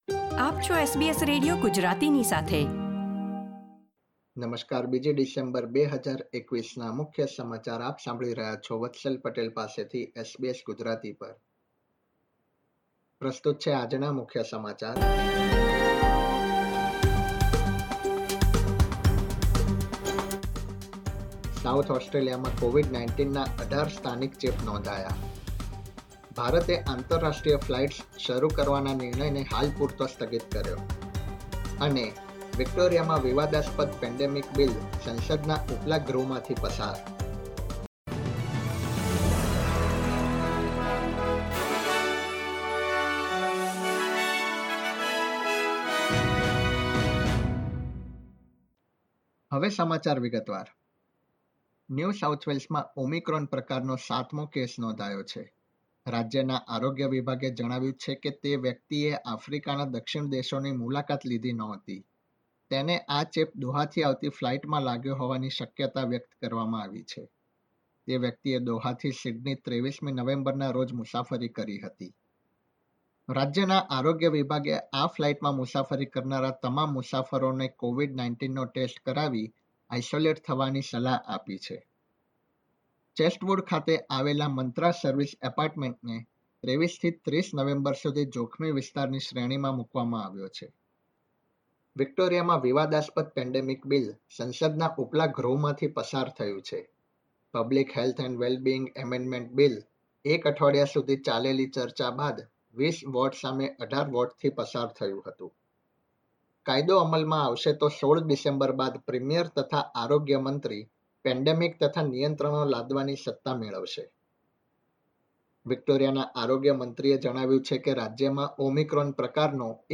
SBS Gujarati News Bulletin 2 December 2021